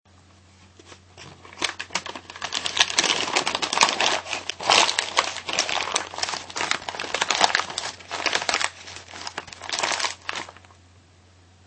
ARRUGANDO PAPEL
Tonos gratis para tu telefono – NUEVOS EFECTOS DE SONIDO DE AMBIENTE de ARRUGANDO PAPEL
Ambient sound effects
arrugando_papel.mp3